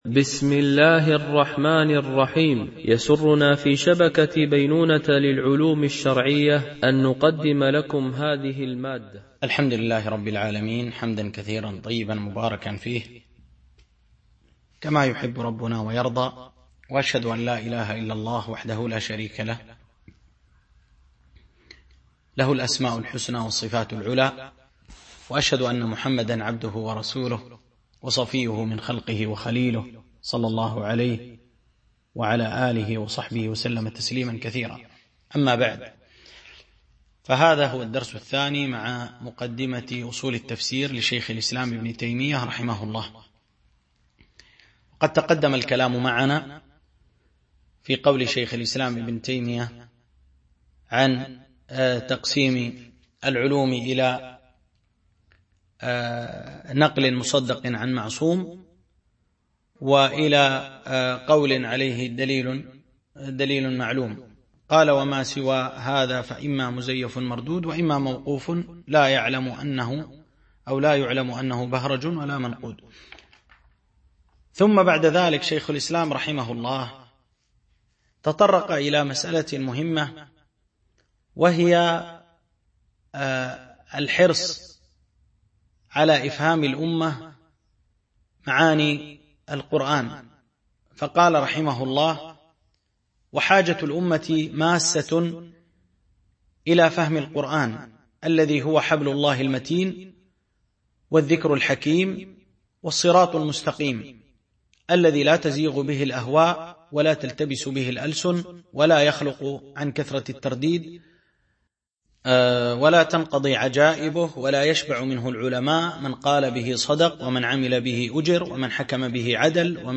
شرح مقدمة في أصول التفسير ـ الدرس 2